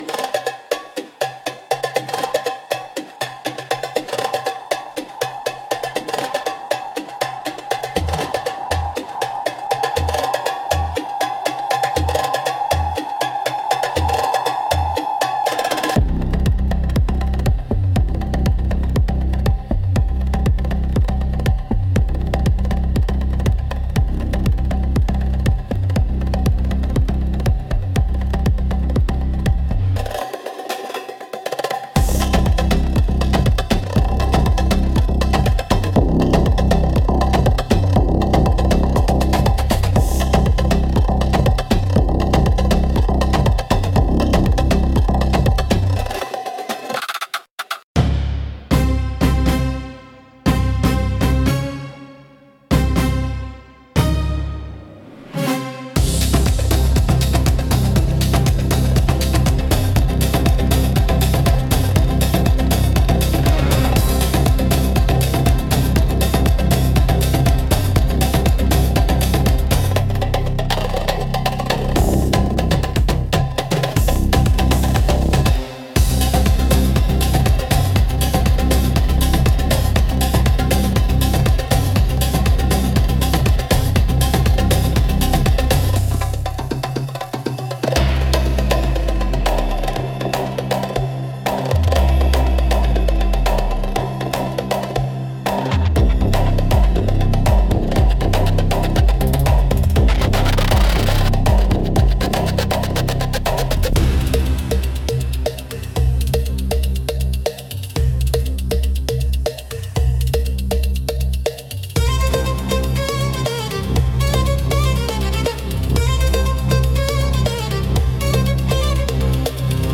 Instrumental - Whispers of the Serpent 2.59